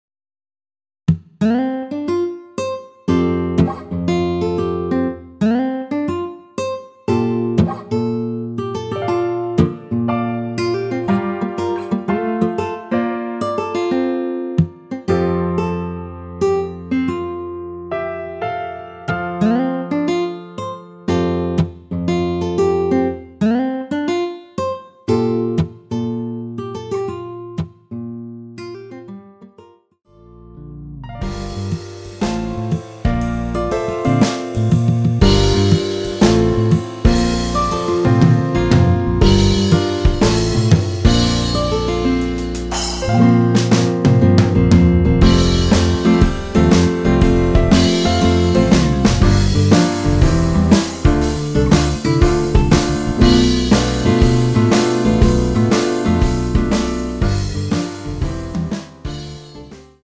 내린 하이퀄리티 MR입니다.
앞부분30초, 뒷부분30초씩 편집해서 올려 드리고 있습니다.
중간에 음이 끈어지고 다시 나오는 이유는
곡명 옆 (-1)은 반음 내림, (+1)은 반음 올림 입니다.